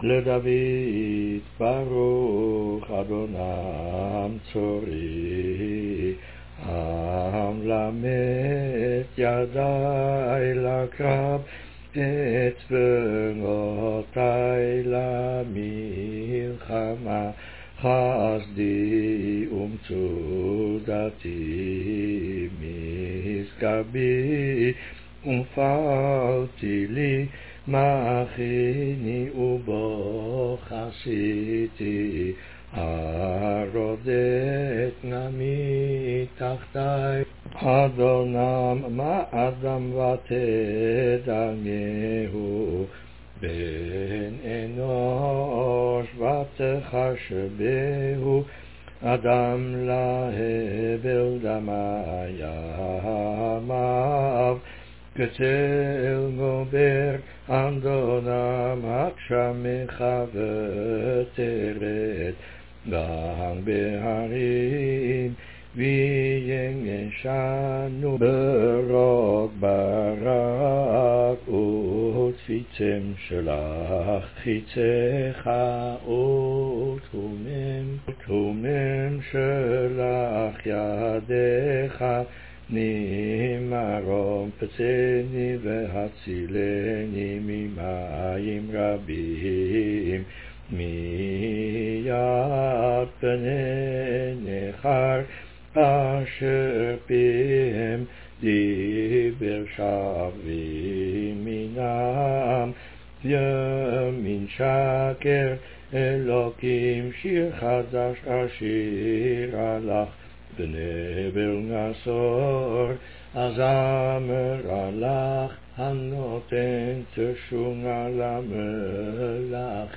All sing